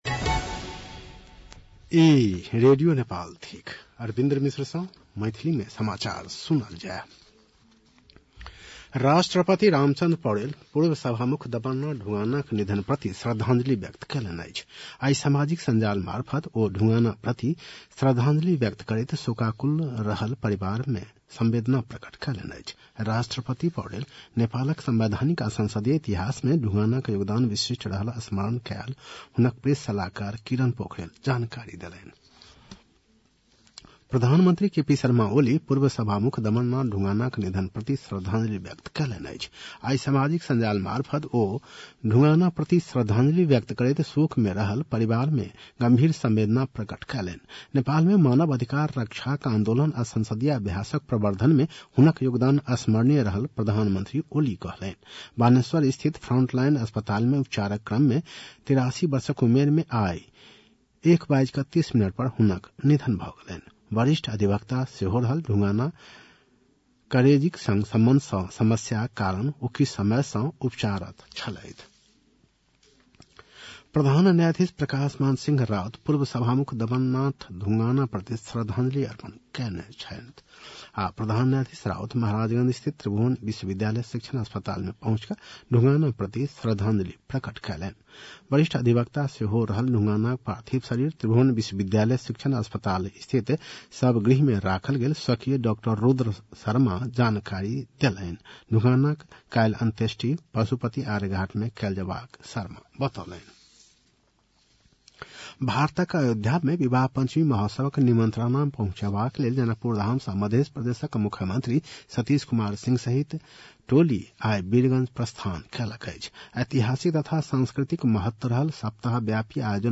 मैथिली भाषामा समाचार : ३ मंसिर , २०८१
Maithali-News-8-2.mp3